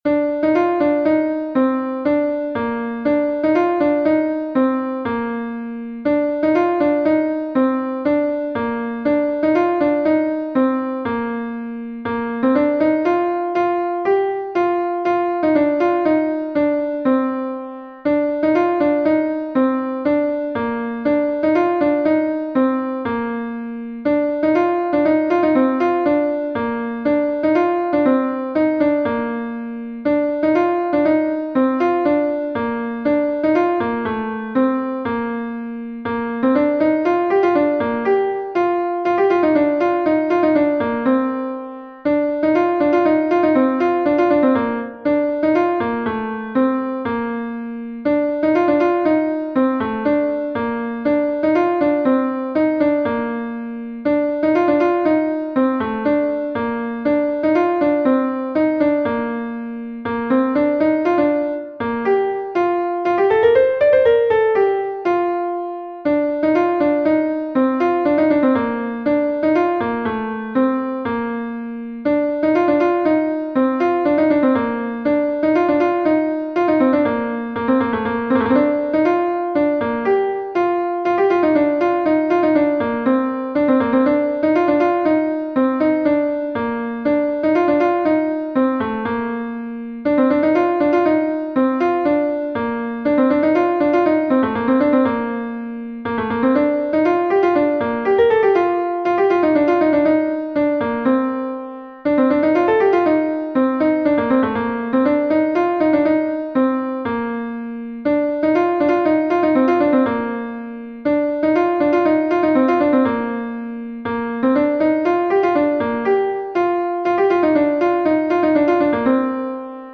Ton Bale Karaez est un Bale de Bretagne